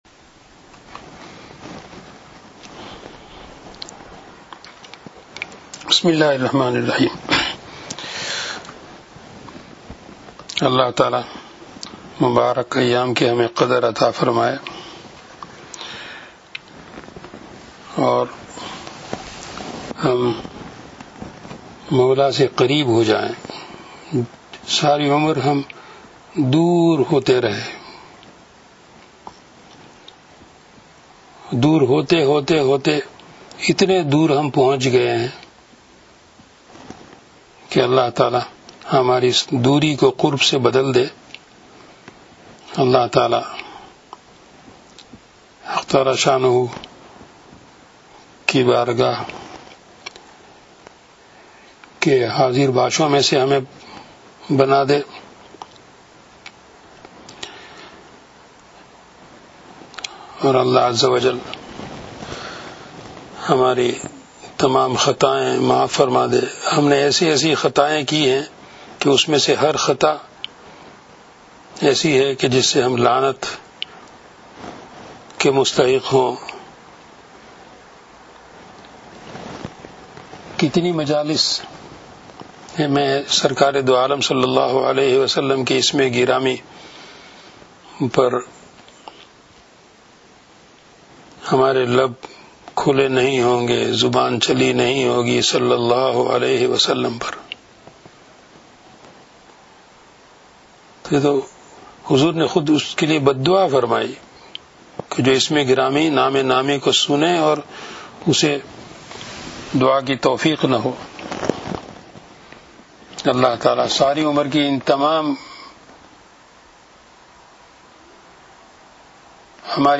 Ramadhan 2014 - Short audio discourses